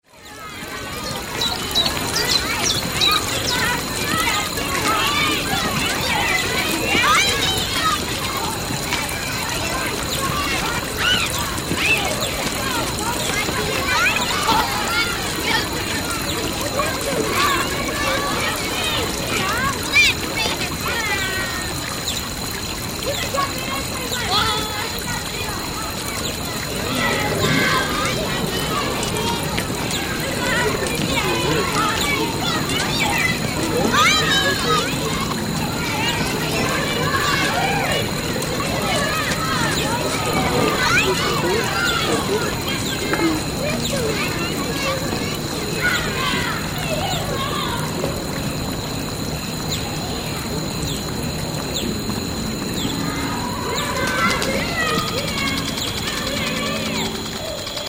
City Park & Fountain
Category: Animals/Nature   Right: Personal